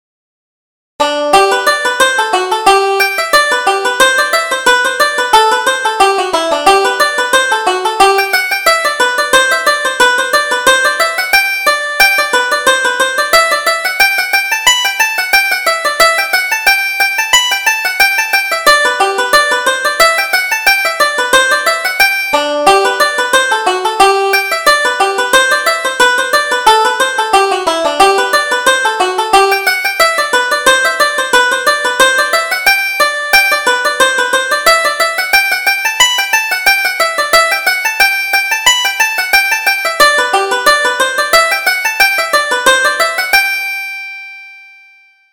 Reel Come to the Dance